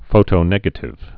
(fōtō-nĕgə-tĭv)